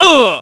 Gladi-Vox_Damage_02.wav